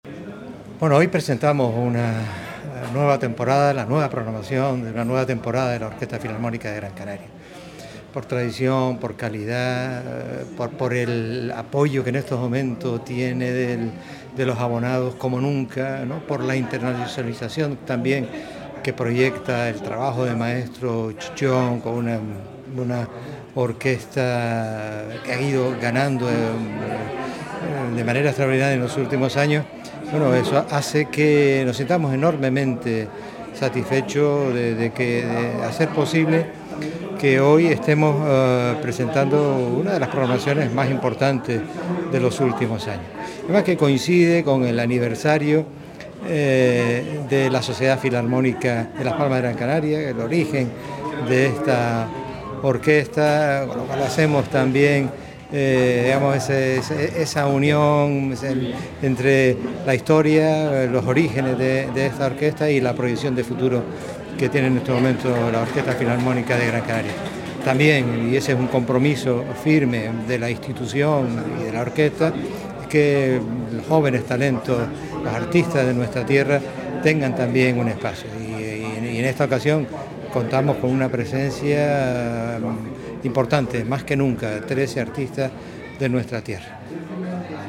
Audios de la presentación